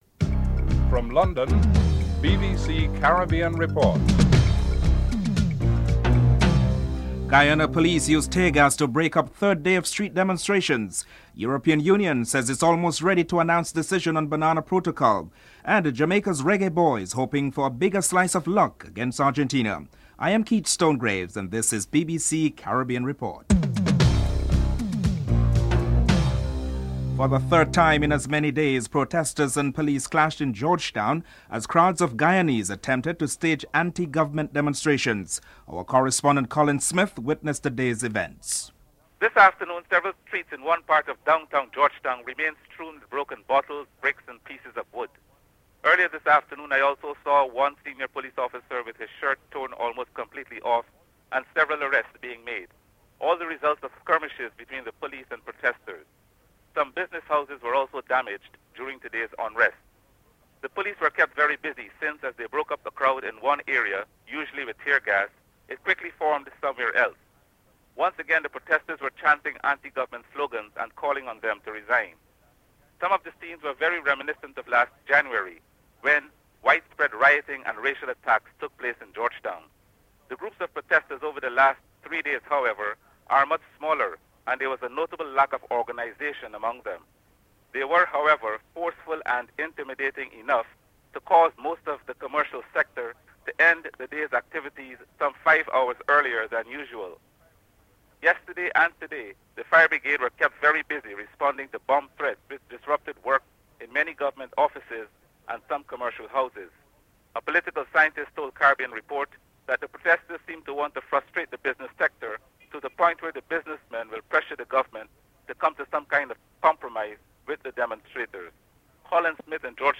9. Recap of top stories (14:56-15:10)